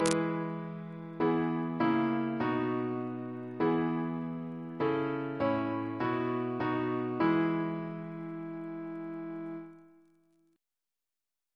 Single chant in E Composer: Rev William Henry Havergal (1793-1870), Hon. Canon of Worcestor Reference psalters: ACB: 90